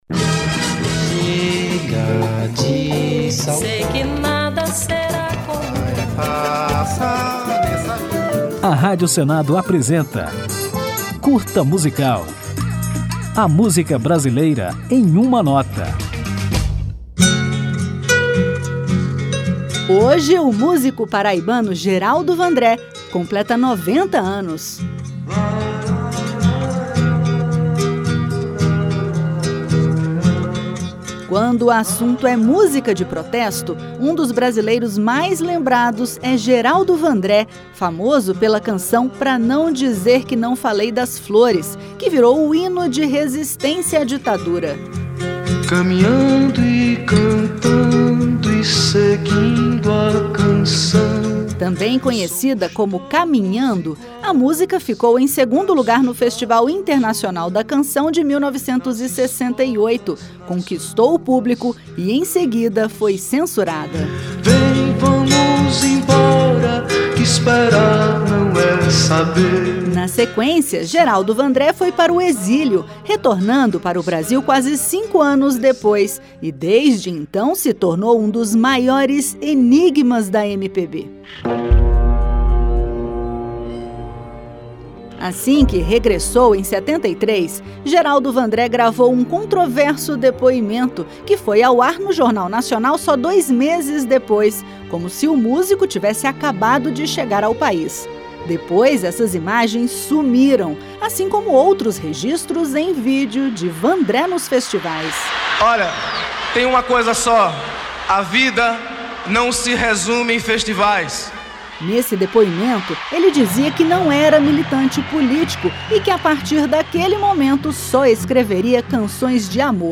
Na homenagem que o Curta Musical preparou, você vai conhecer a história do músico e entender porque ele se tornou um dos maiores mistérios da MPB, depois que foi perseguido pela Ditadura Militar. Ao final do programa, ouviremos Geraldo Vandré na música Disparada, vencedora do Festival da MPB de 1966.